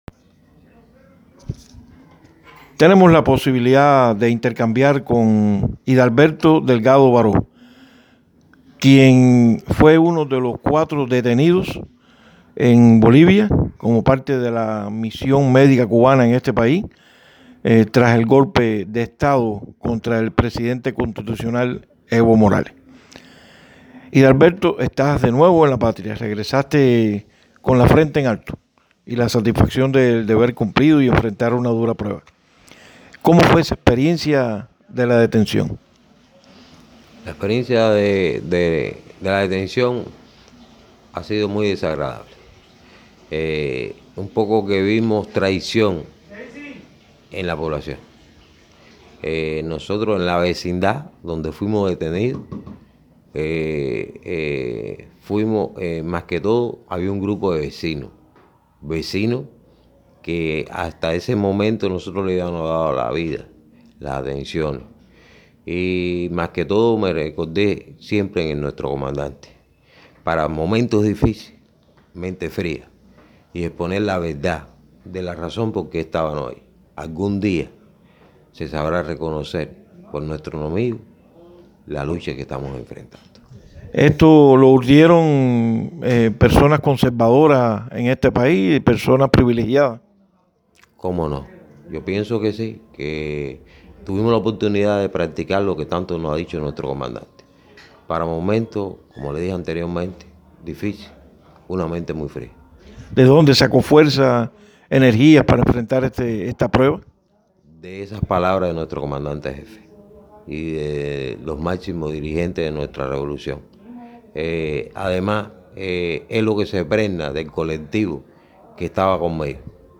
Entrevista en audio: